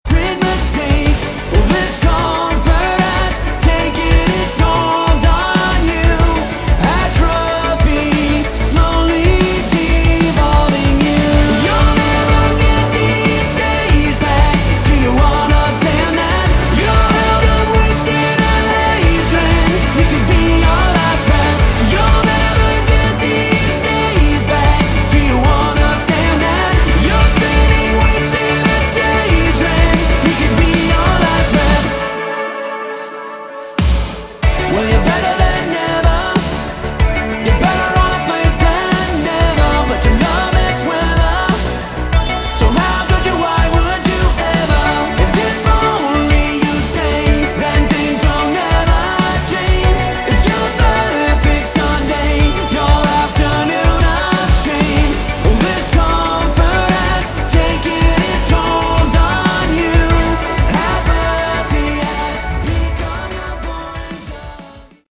Category: AOR
Vocals, Guitars, Synths
Drums, Percussion